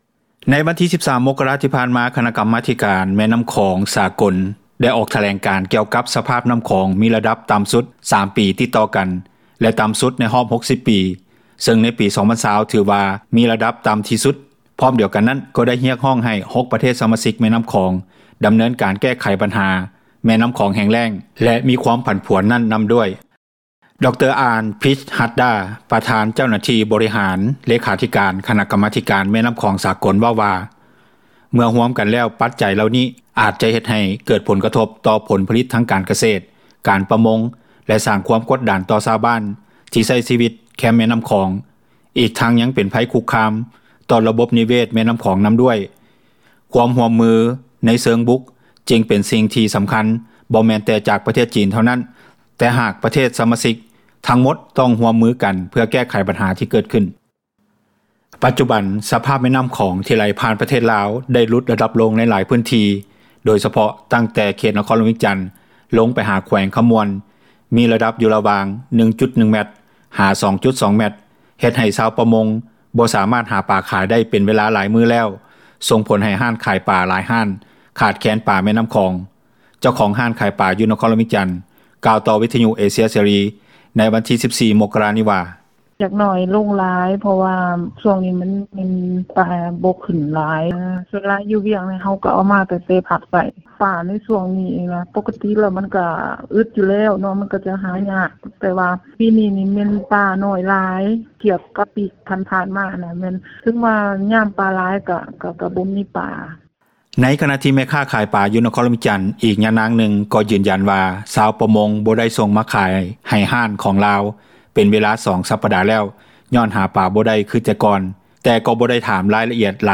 ໃນຂະນະທີ່ແມ່ຄ້າຂາຍປາ ຢູ່ນະຄອນຫຼວງວຽງຈັນອີກຍານາງນຶ່ງ ກໍຢືນຢັນວ່າ ຊາວປະມົງບໍ່ໄດ້ສົ່ງມາຂາຍໃຫ້ຮ້ານຂອງລາວ ເປັນເວລາ 2 ສັປດາແລ້ວ ຍ້ອນຫາປາບໍ່ໄດ້ຄືແຕ່ກ່ອນ ແຕ່ກໍບໍ່ໄດ້ຖາມລະອຽດຫຼາຍໄປກ່ວານັ້ນວ່າ ເກີດຈາກສາເຫດໃດກັນແທ້.
ຊາວບ້ານຢູ່ແຂວງເລີຍທ່ານນຶ່ງກ່າວວ່າ: